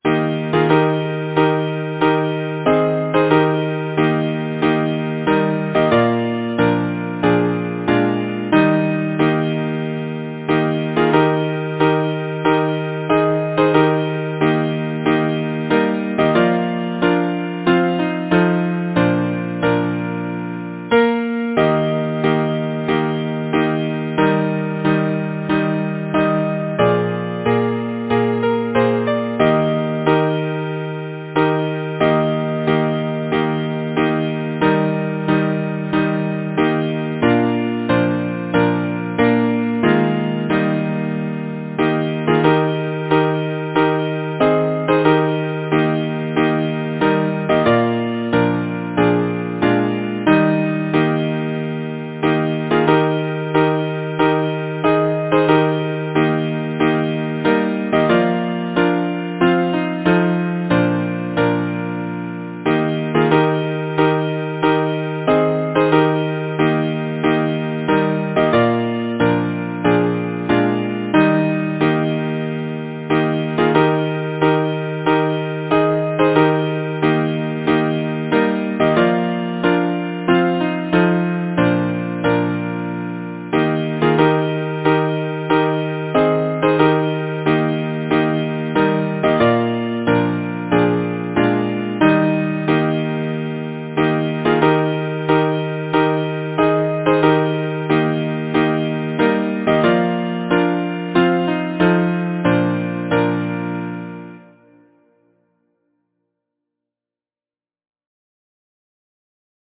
Title: The Sweet South-Wind Composer: Charles King Langley Lyricist: Eden Reeder Latta Number of voices: 4vv Voicing: SATB Genre: Secular, Partsong
Language: English Instruments: A cappella